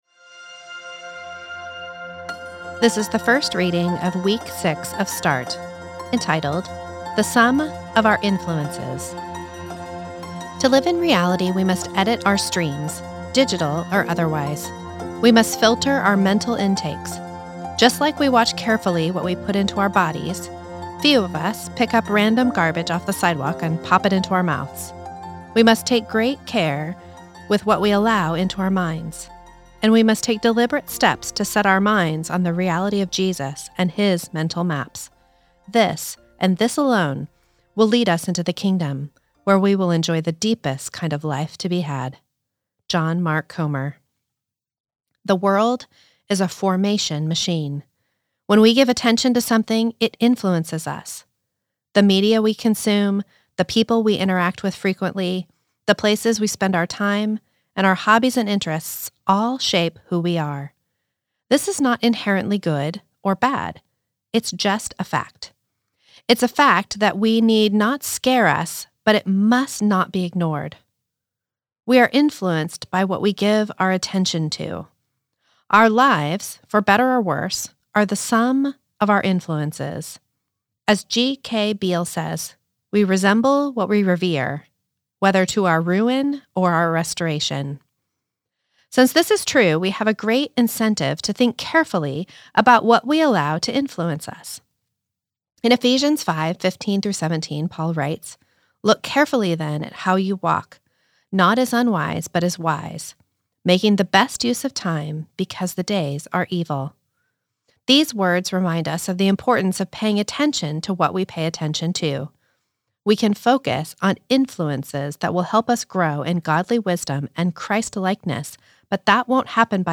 This is the audio recording of the first reading of week six of Start, entitled The Sum of Our Influences.